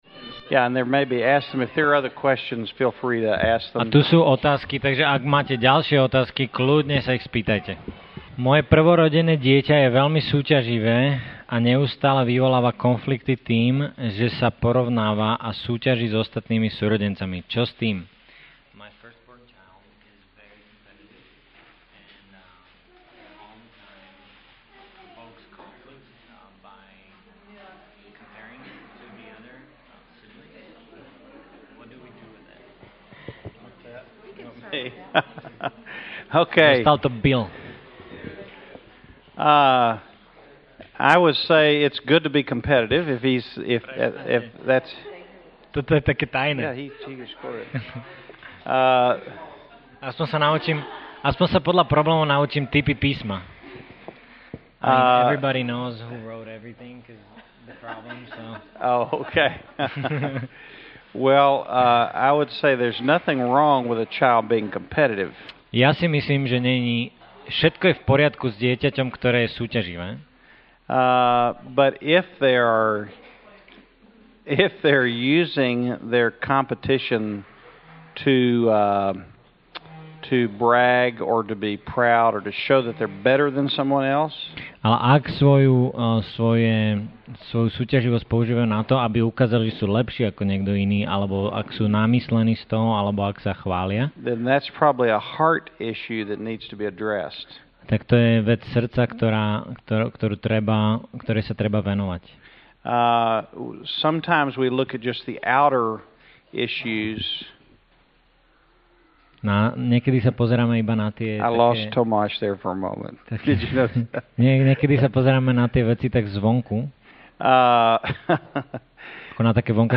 Nahrávka kázne Kresťanského centra Nový začiatok z 6. júla 2007